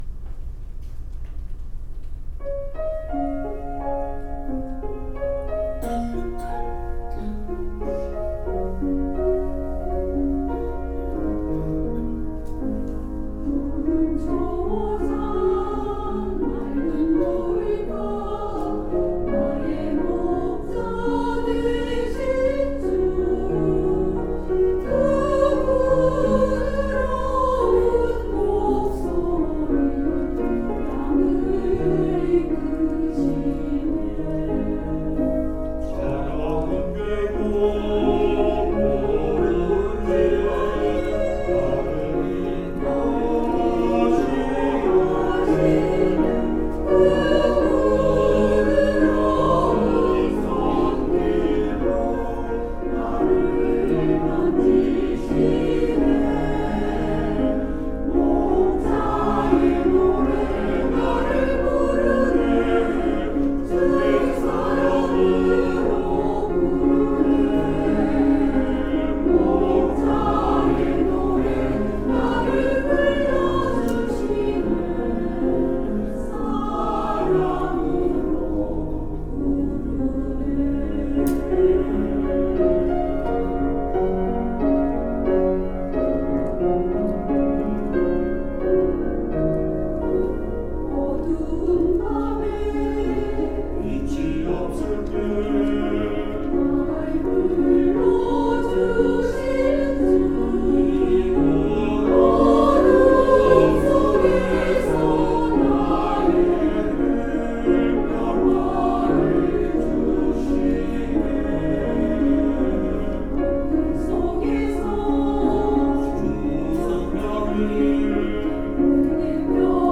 찬양대
[주일 찬양] 목자의 노래